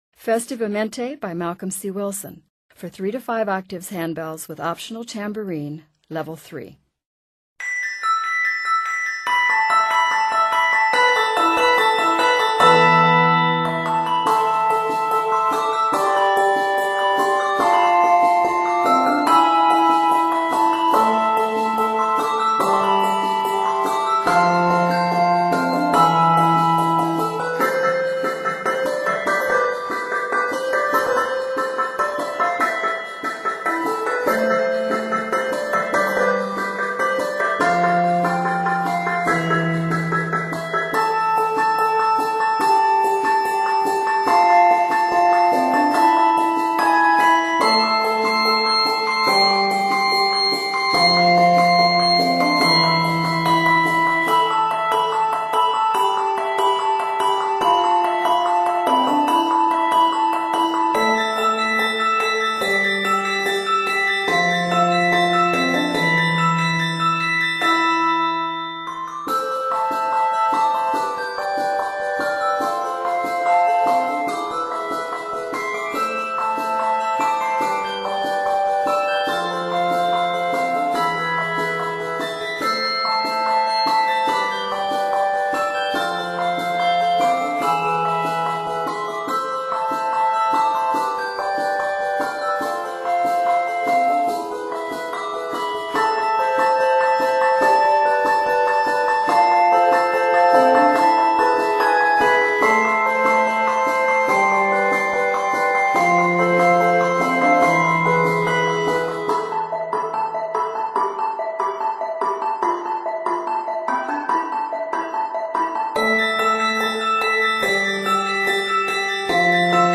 N/A Octaves: 3-5 Level